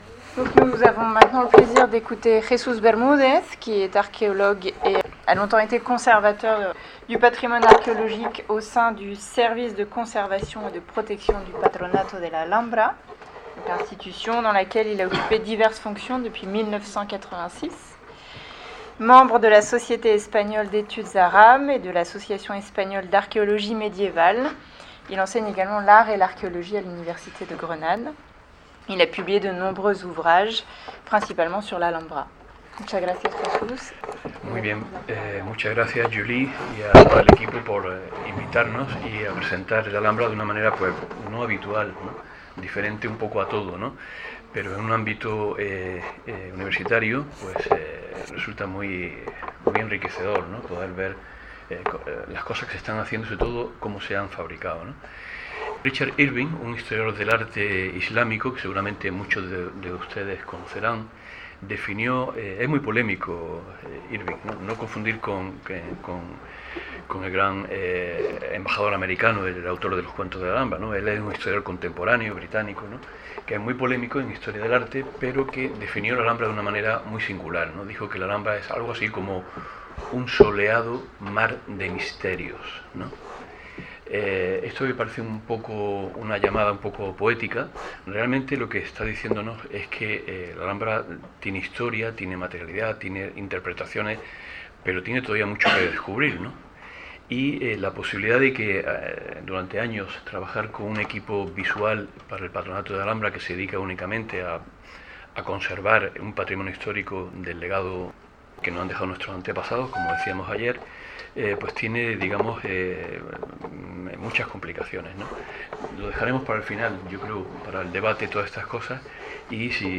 Podcast de una ponencia sobre la construcción de la Alhambra, su arquitectura y el aspecto simbólico de algunos aspectos de este extraordinario edificio.